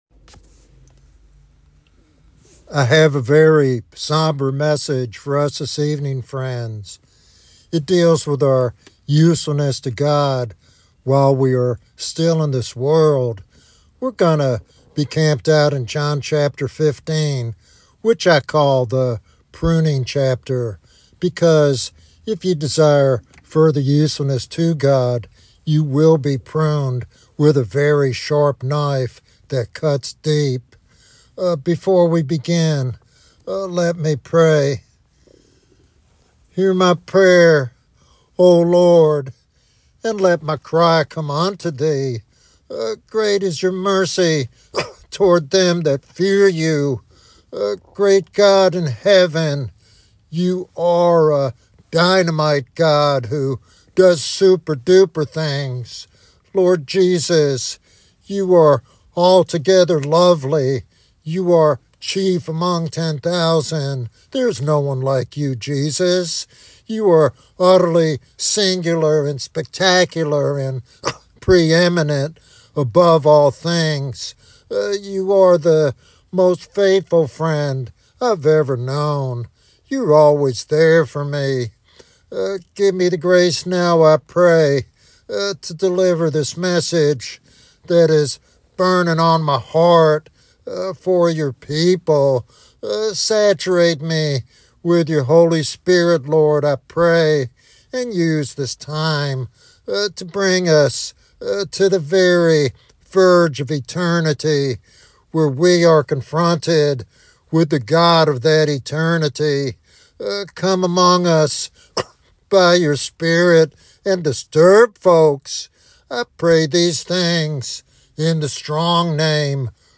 This sermon calls listeners to a serious commitment to Christ and a readiness to experience His unsettling yet transformative presence.